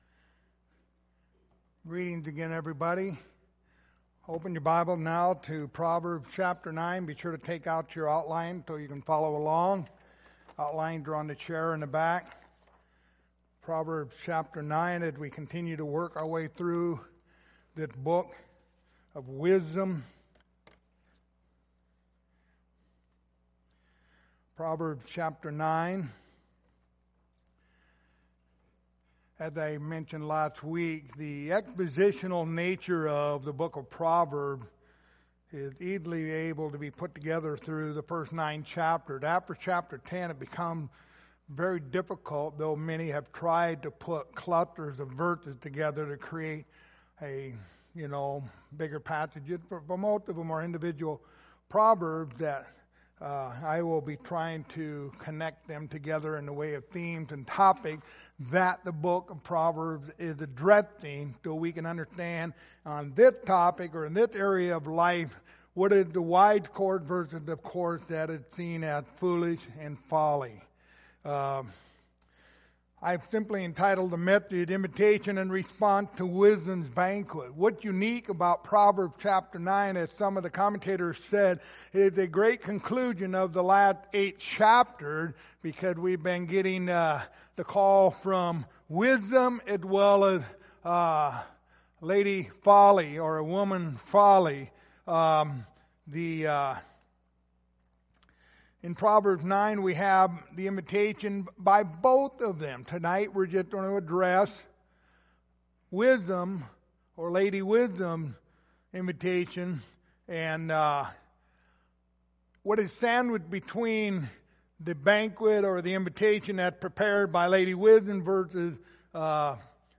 Passage: Proverbs 9:1-9 Service Type: Sunday Evening